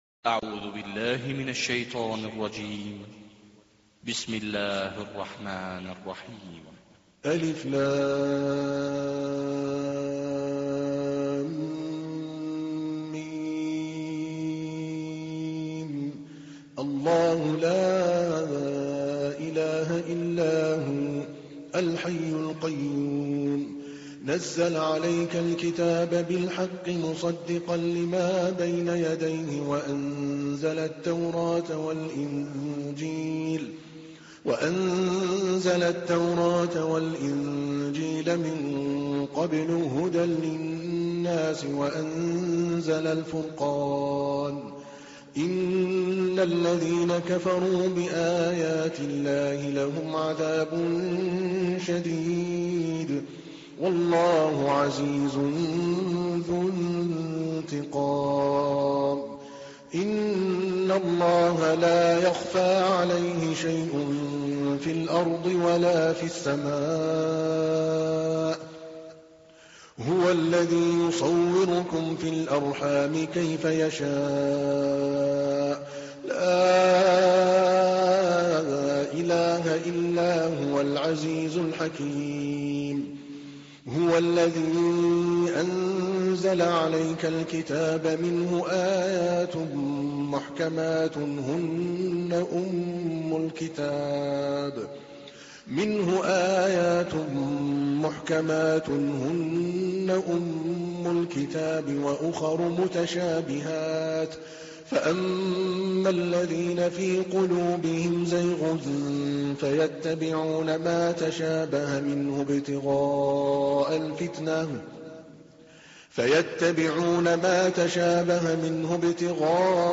تحميل : 3. سورة آل عمران / القارئ عادل الكلباني / القرآن الكريم / موقع يا حسين